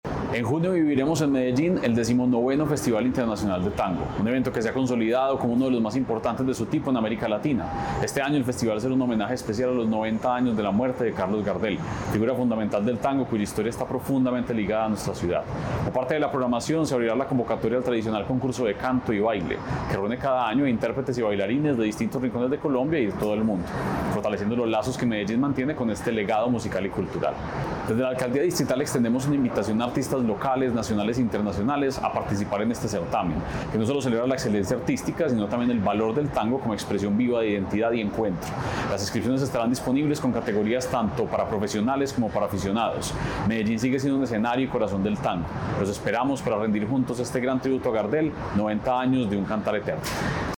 Palabras-de-secretario-de-Cultura-Ciudadana-Santiago-Silva-Jaramillo.mp3